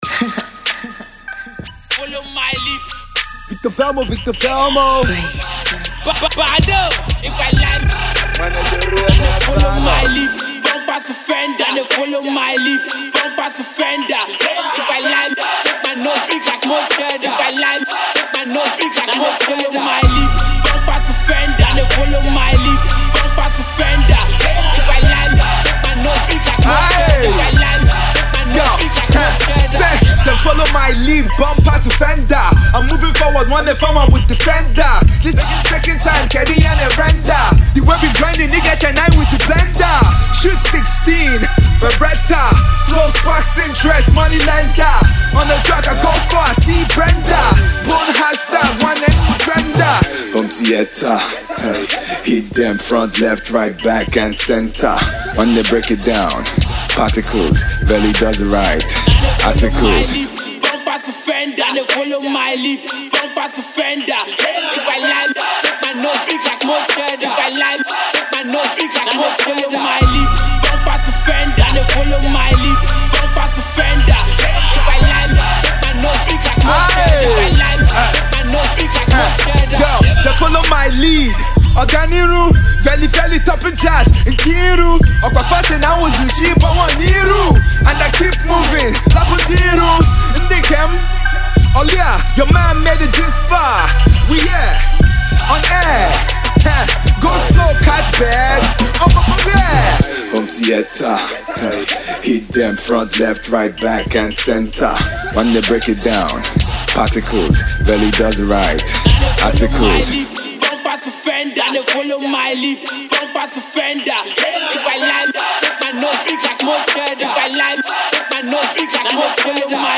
Prolific urban-indigenous rapper
Hip Hop